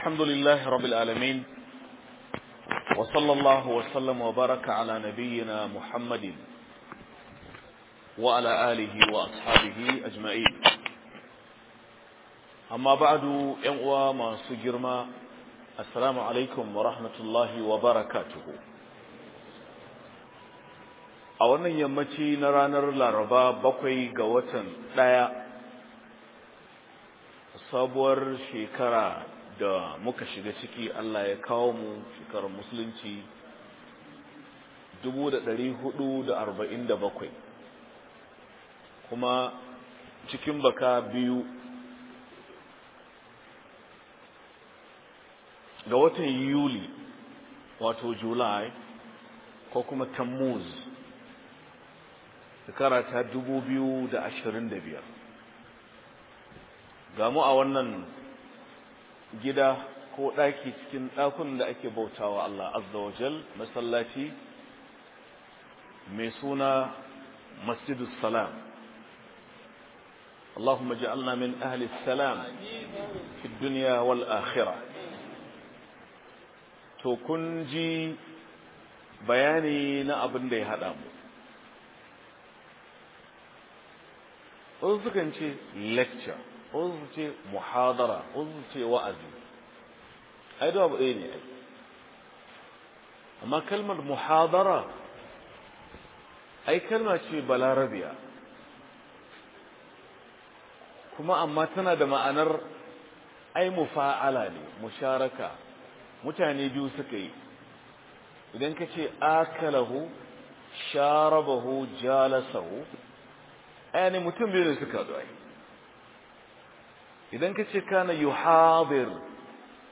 TASIRIN FITINTINU ACIKIN AL'UMMAH - MUHADARA